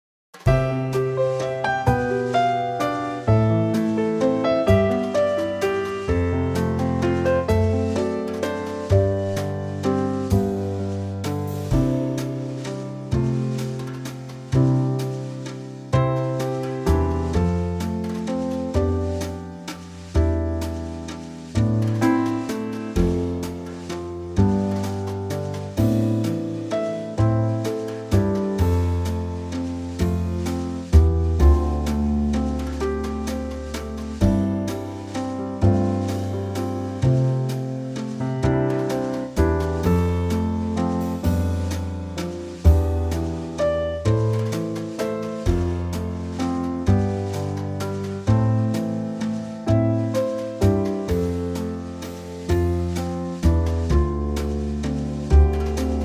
Unique Backing Tracks
key - C to Db - vocal range - G to Bb